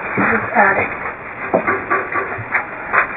Some E.V.P. captured at St. Mary's Hospital:
EVP_3 taps_StM  While investigating the attic, I asked the spirits to make a noise for us, and these three taps were captured.